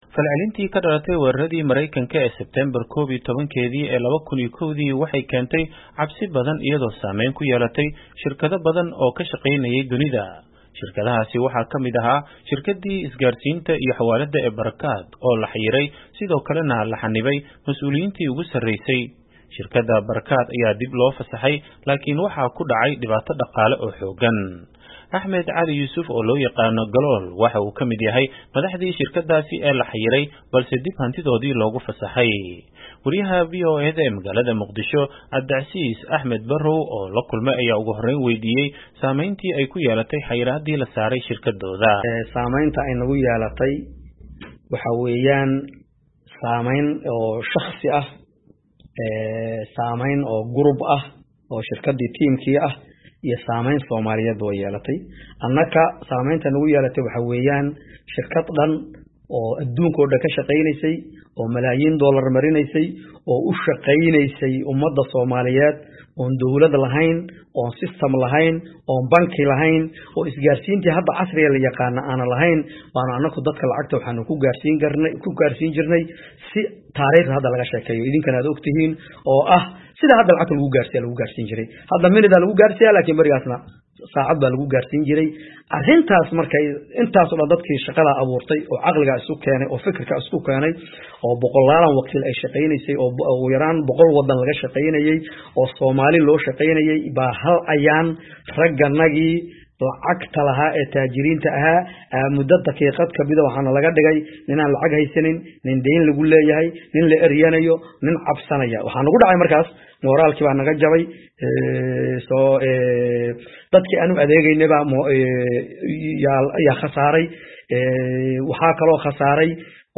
Wareysi: Sidee falcelintii Maraykanka ee weeraradii 9/11 u saameysay shirkaddii Al-Barakaat?